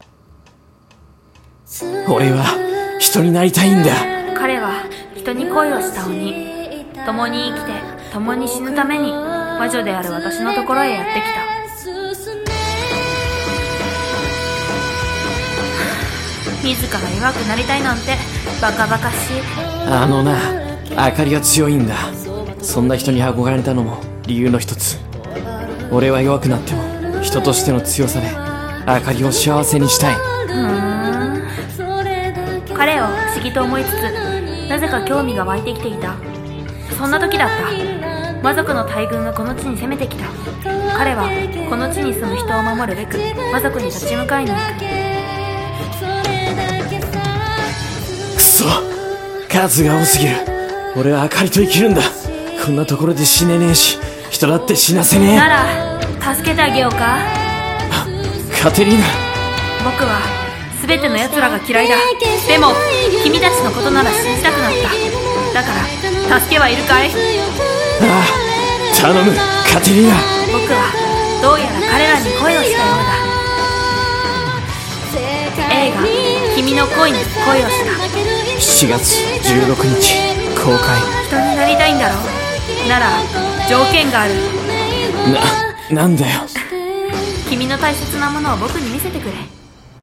映画予告風声劇「君の恋に恋をした」